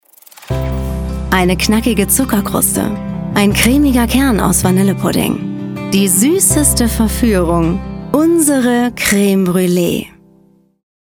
markant, dunkel, sonor, souverän
Mittel minus (25-45)
Commercial (Werbung)